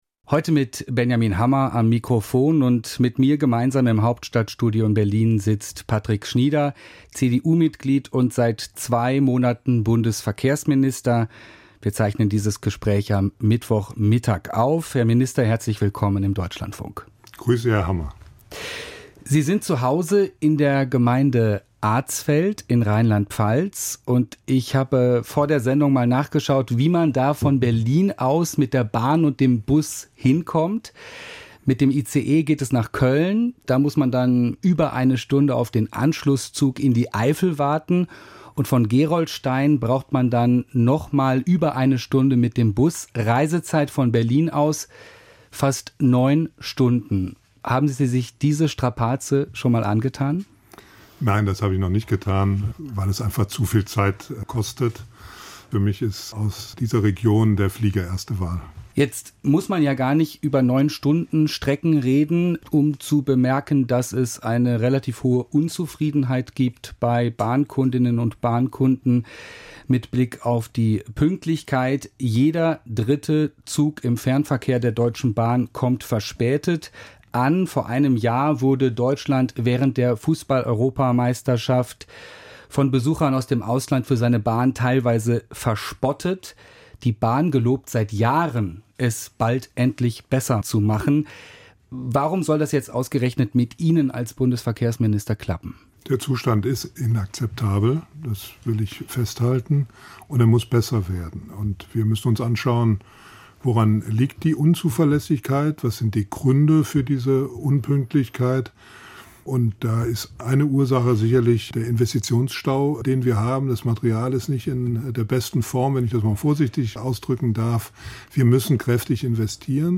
Interview Schnieder, Patrick, Bundesverkehrsminister, CDU